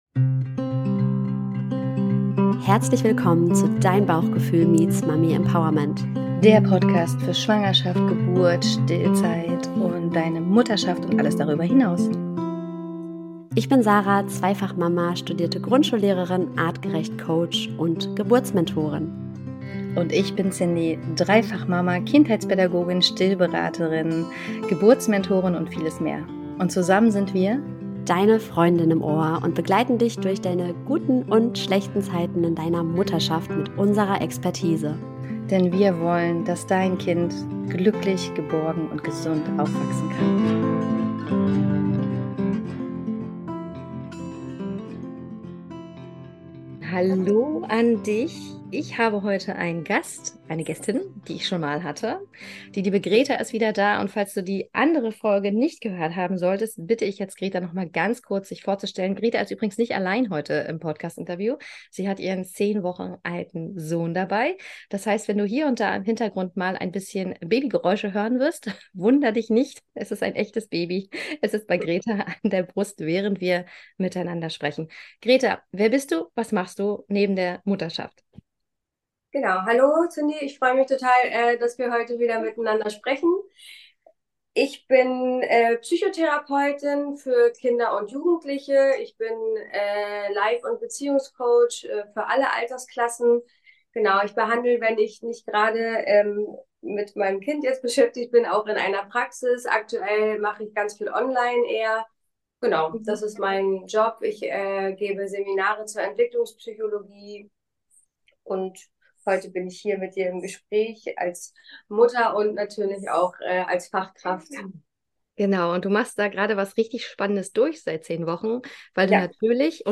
Heute spreche ich mit der Psychotherapeutin und Beziehungscoach